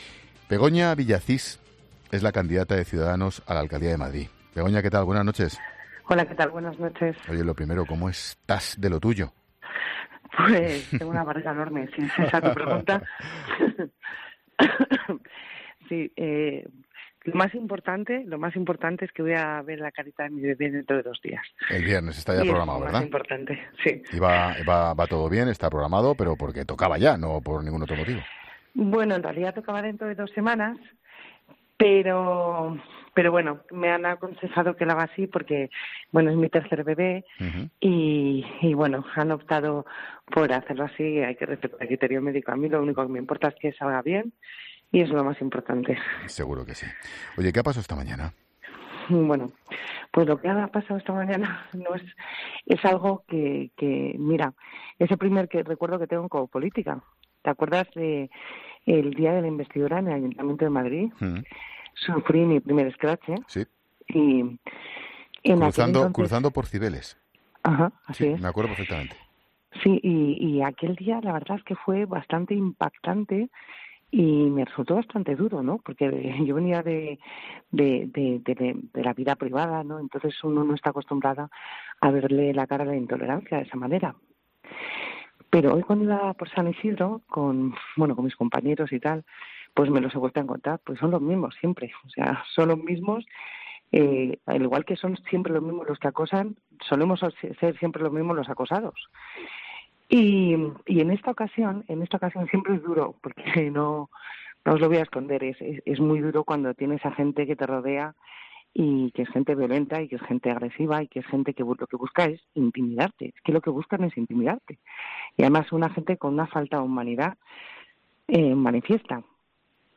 En este día de San Isidro ha pasado por los micrófonos de 'La Linterna', la candidata de Cuidadanos a la Alcaldía de Madrid, Begona Villacís.